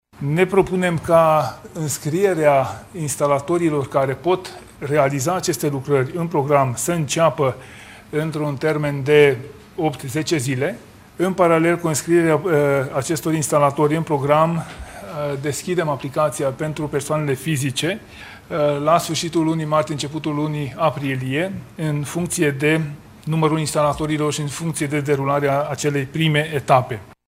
Anunțul a fost făcut de ministrul Mediului, Apelor şi Pădurilor, Tanczos Barna, azi (joi), la finalul şedinţei de Guvern.